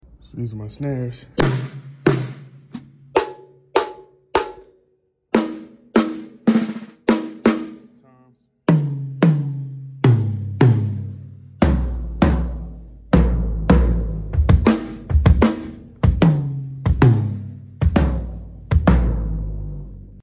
Low drum tuning